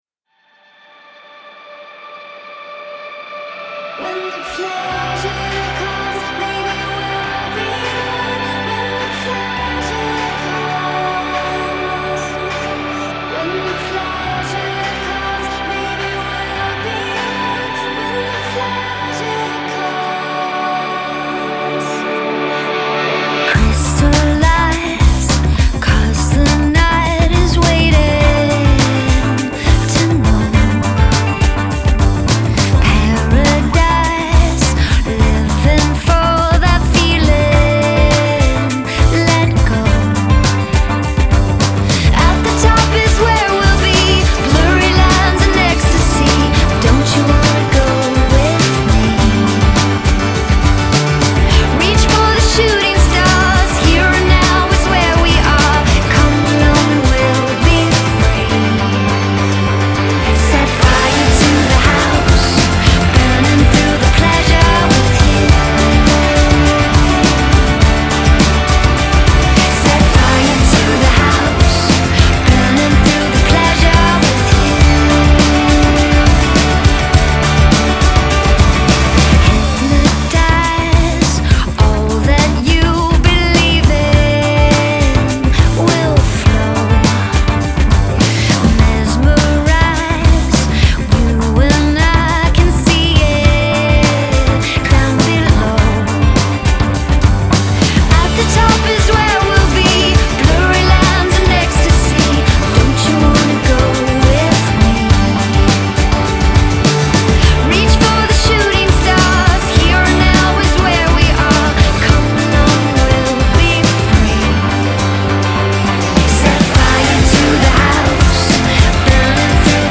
Genre: Indie Pop